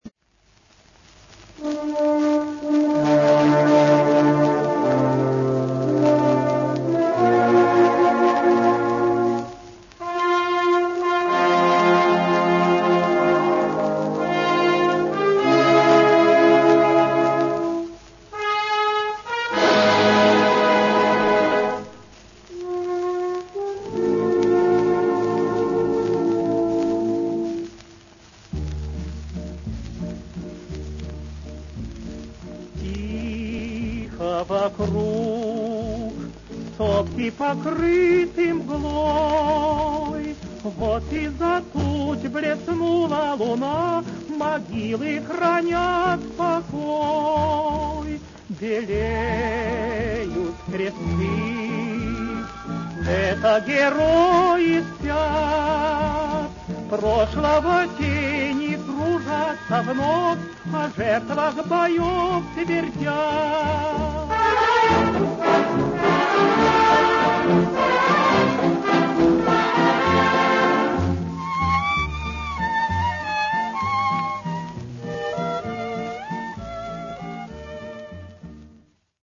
1) За границей этот вальс называли «национальным русским вальсом».
vals.mp3